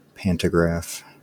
Ääntäminen
IPA : /ˈpantəɡɹɑːf/ IPA : /ˈpantəɡɹaf/